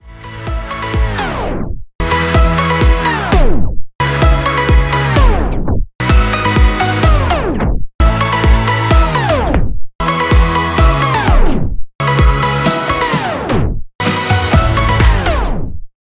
私が作ったEDMの中でエフェクトを一つずつ使用してみましたので、ここで試しに気になるものがあるか聞いてみてください。
これらは効果がわかりやすいように大げさに使用しているので曲中ではこんな使い方はしませんが。
(個別の楽器に使うとか、一部のトラックに使う方が良さそうですが曲全体に適用しちゃってます)
Tapestop↓
グリットテープストップ.mp3